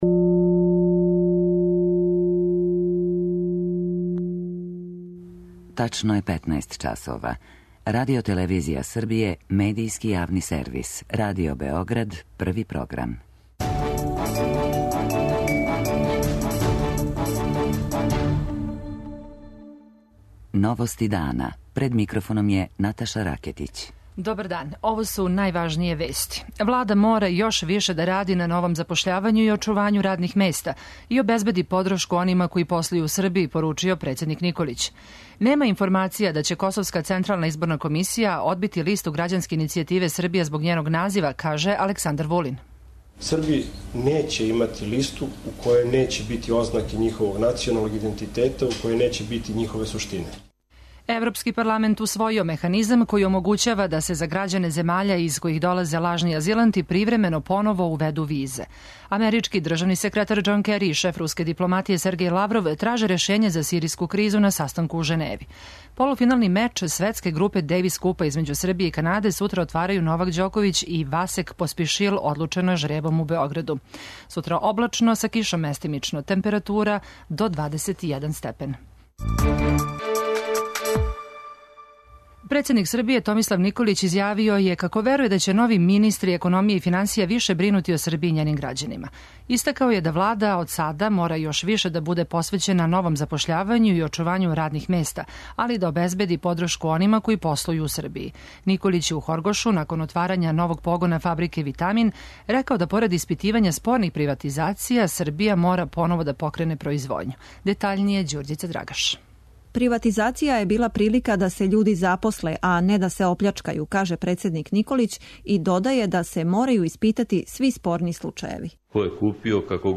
О томе шта та одлука конкретно занчи за нашу земљу, у Новостима дана разговарам са Ласлом Варгом, замеником скупштинског одбора за евроинтеграције и шефом делегације Србије у Европском парламенту.
преузми : 15.44 MB Новости дана Autor: Радио Београд 1 “Новости дана”, централна информативна емисија Првог програма Радио Београда емитује се од јесени 1958. године.